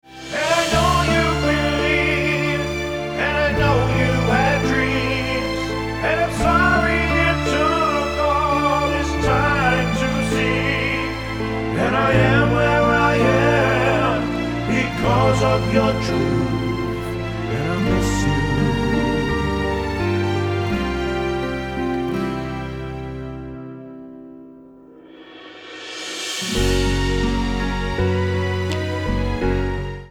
--> MP3 Demo abspielen...
Tonart:F-F#-G-Ab mit Chor